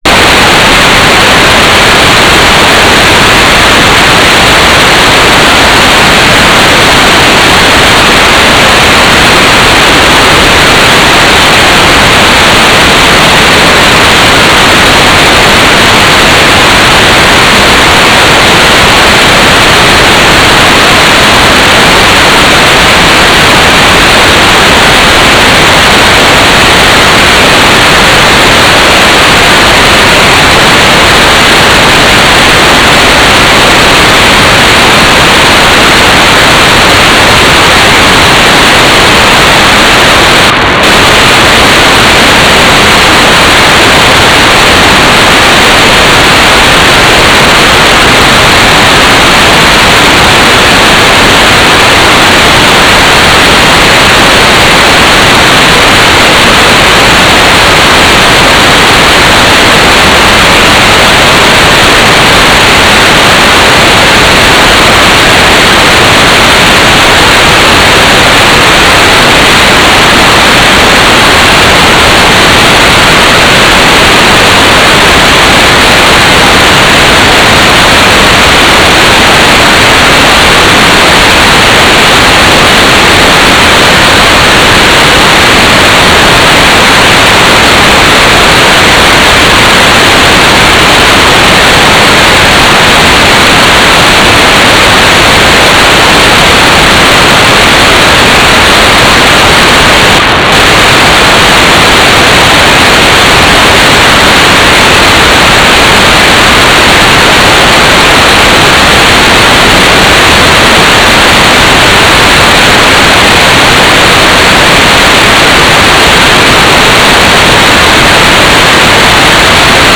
"transmitter_description": "GMSK4k8 Mobitex TLM",
"transmitter_mode": "GMSK",